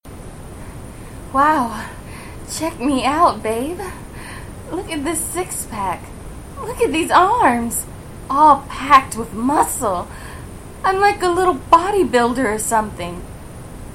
Please note, I cannot eliminate all of the background noise, but this is an option for those of you with more sensitive ears.